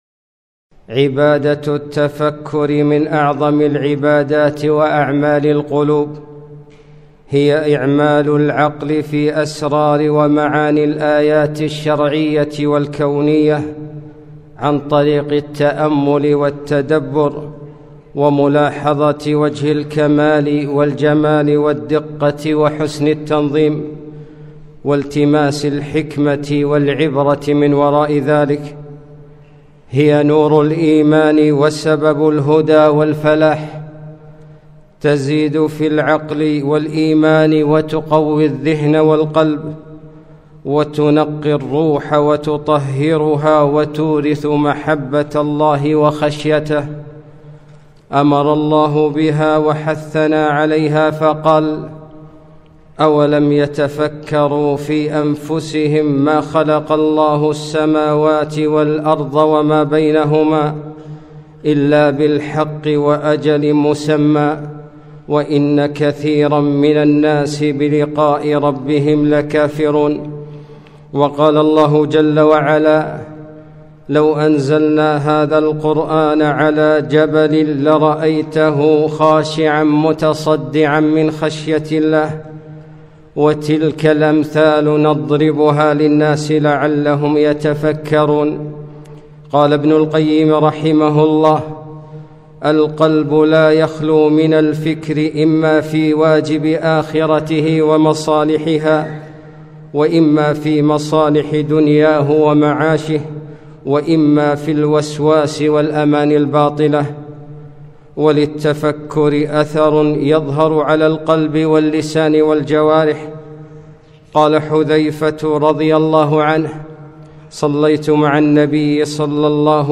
خطبة - أفلا تتفكرون